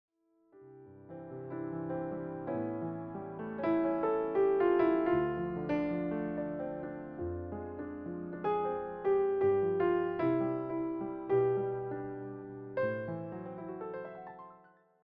warm and familiar piano arrangements
solo piano